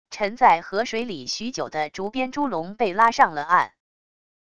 沉在河水里许久的竹编猪笼被拉上了岸wav音频